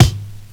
Kicks
Medicated Kick 4.wav